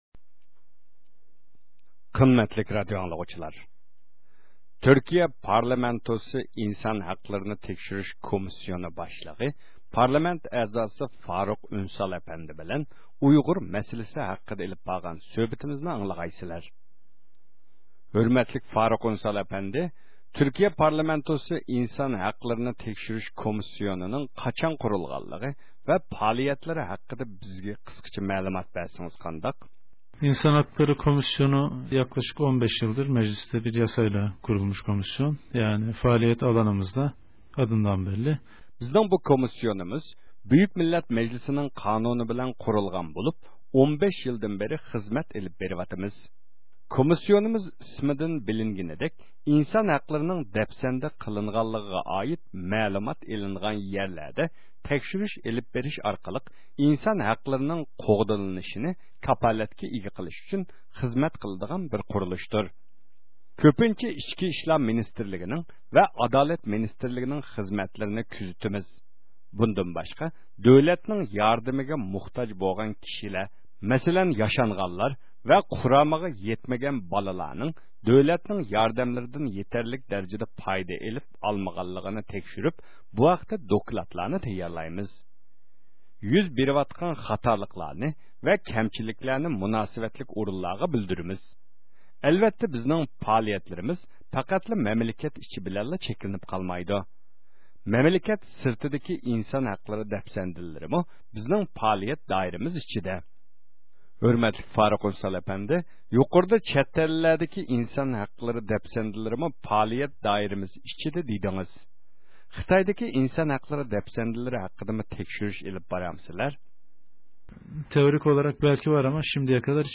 تۈركىيە پارلامېنتى كىشىلىك ھوقۇق تەكشۈرۈش كومىتېتىنىڭ باشلىقى فارۇخ ئۇنسال ئەپەندىنى زىيارەت قىلىپ